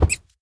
mob / rabbit / hurt1.ogg